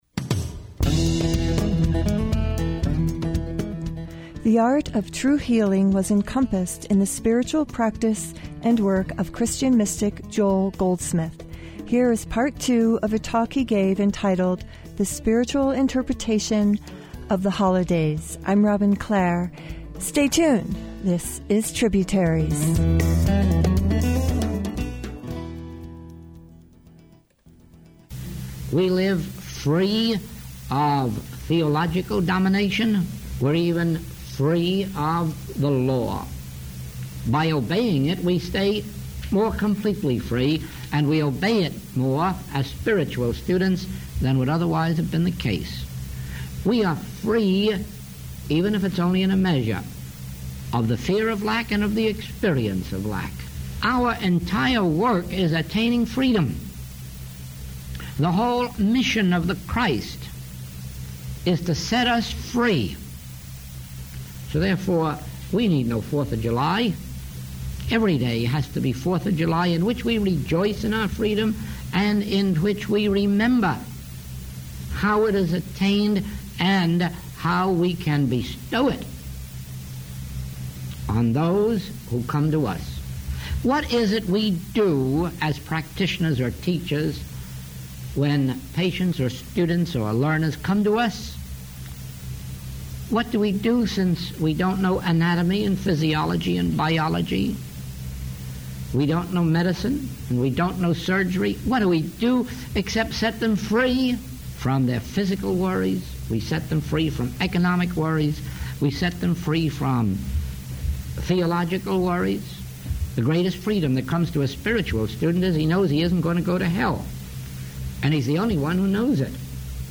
This new to tributaries talk is entitled The Spiritual Meaning of Holidays